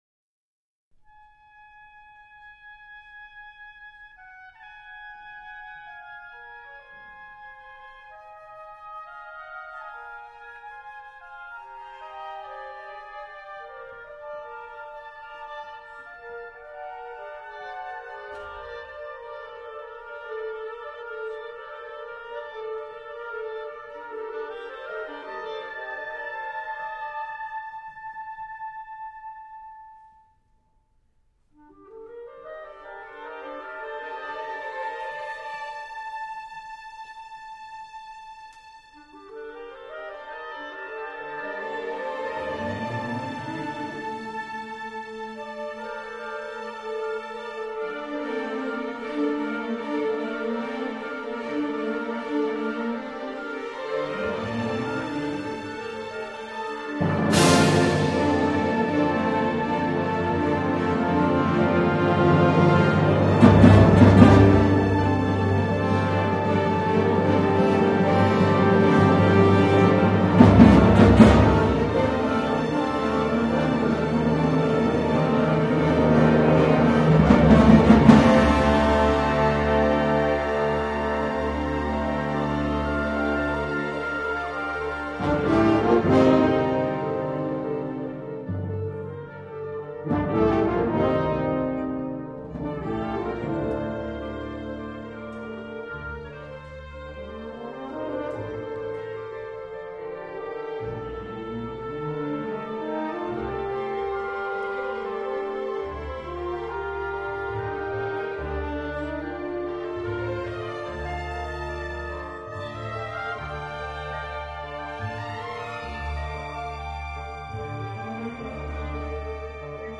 Early Reflection (Orchestra)
In this piece, the concept of early reflection rules the structure and material of the music. Instead of using electronic devices or the echoes of a true chamber, the piece is scored to provide its own reverberation in the form of multiple canons at the unison.